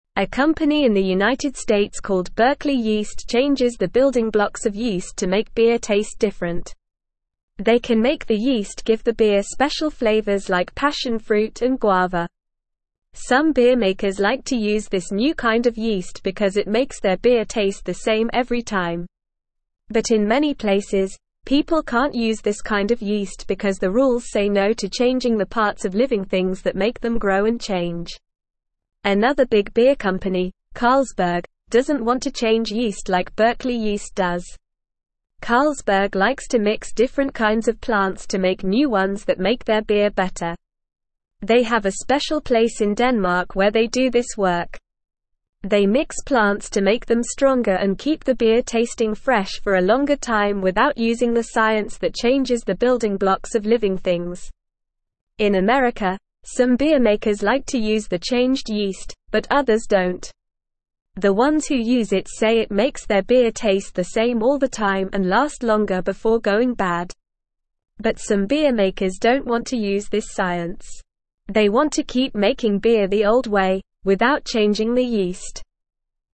Normal
English-Newsroom-Lower-Intermediate-NORMAL-Reading-Changing-Yeast-to-Make-Beer-Taste-Different.mp3